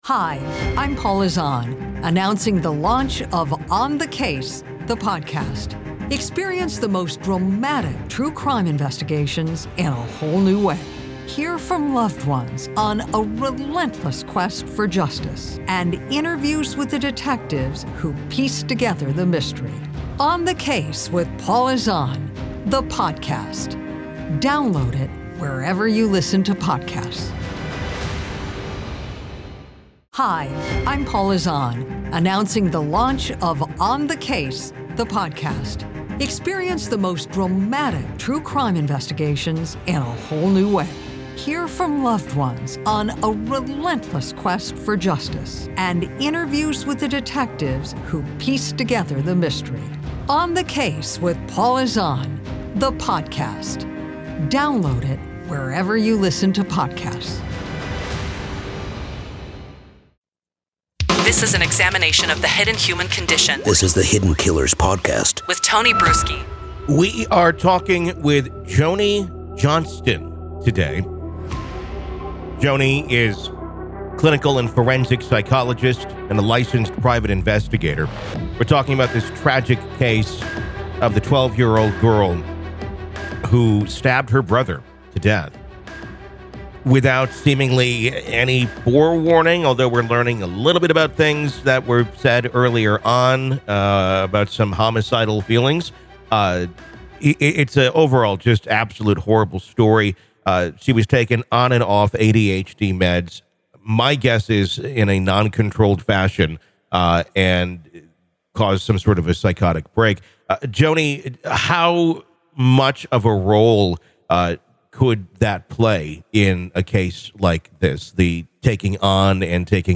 True Crime Today | Daily True Crime News & Interviews / EVIL Child Or Poor Med Management?